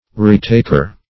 Retaker \Re*tak"er\, n. One who takes again what has been taken; a recaptor.
retaker.mp3